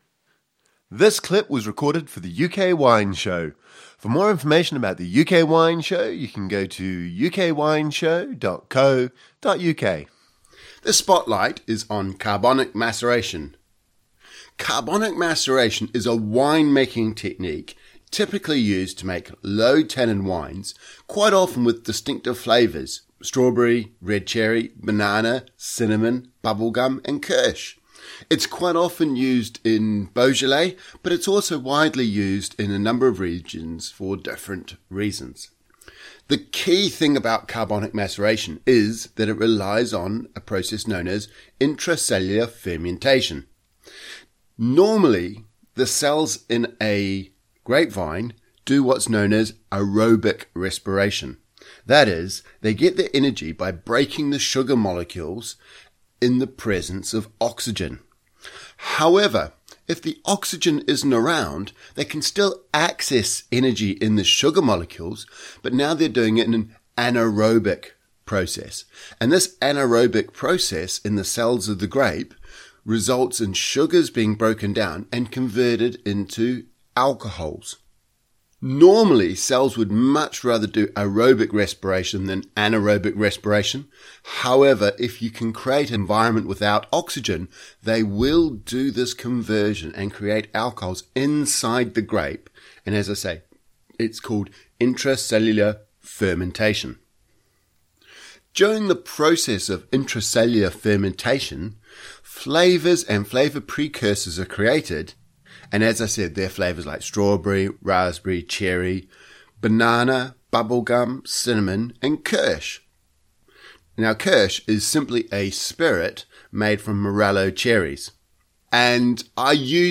UK Wine Show 667 Spotlight on Carbonic Maceration Interview Only.mp3